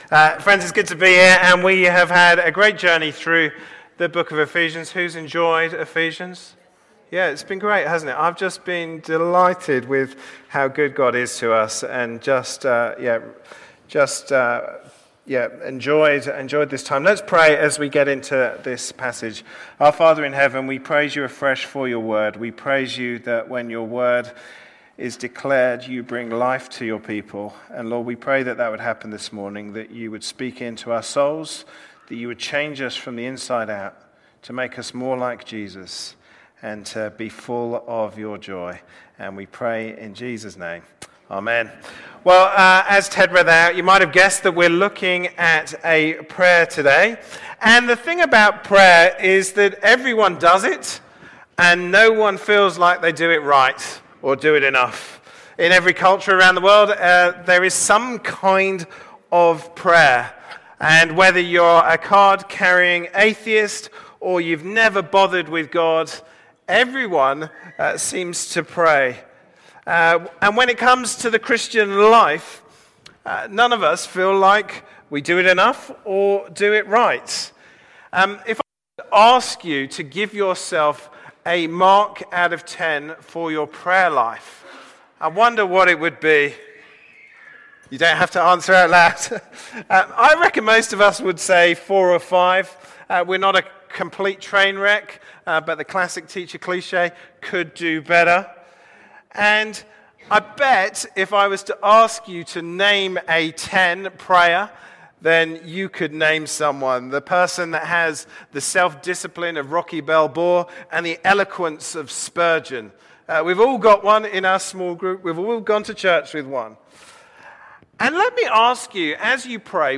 - God's Plan for His Church Passage: Ephesians 3:14-21 Service Type: Sunday morning service Topics: prayer « Luke 9:1-20 Palm Sunday »